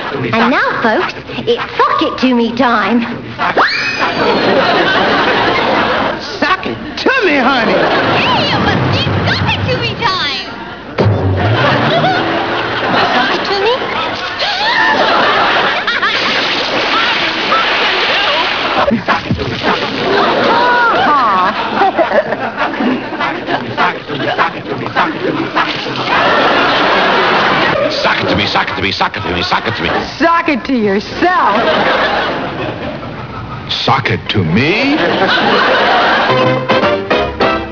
laugh-04.wav